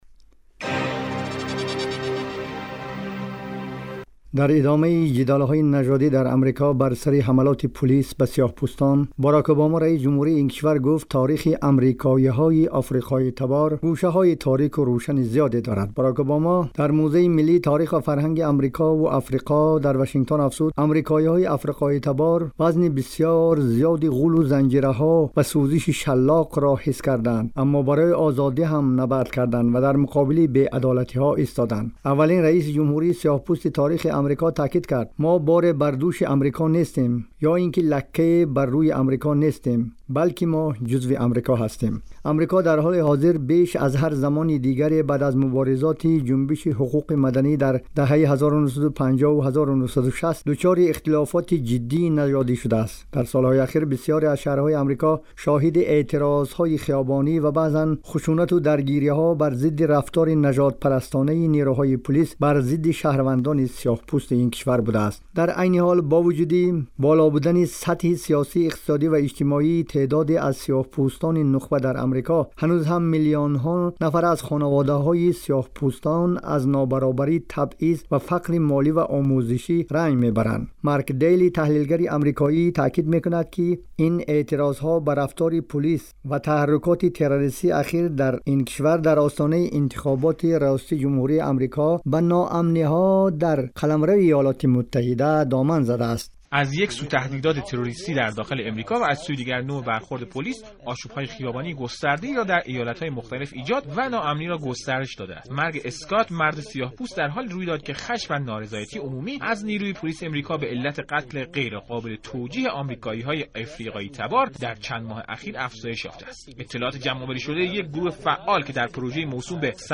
дар гузориши вижа ба мавзӯи нобаробарии сиёҳпӯстон бо сафедпӯстон дар ҷомеаи Амрико пардохтааст, ки дар идома мешунавед: